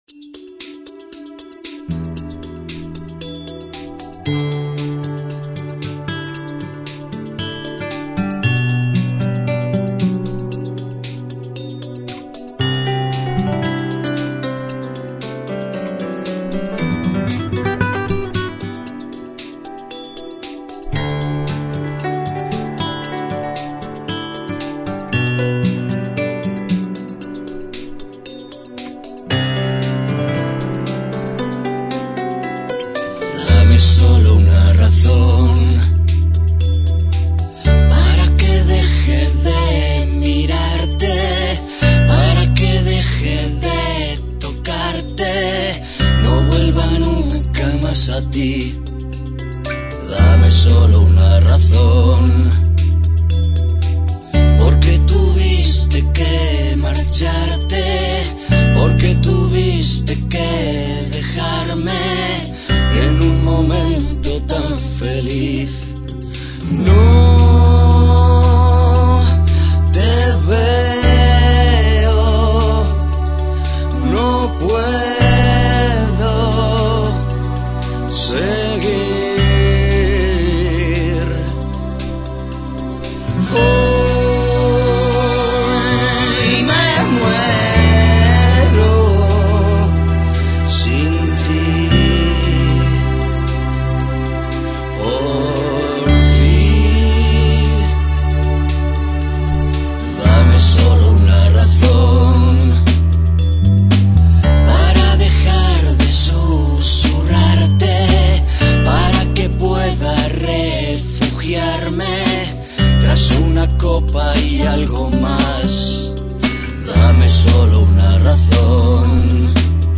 Llenos de melancolia, fuerza y magia
guitarra española y secuencias
Bajo
Guitarra eléctrica
Bateria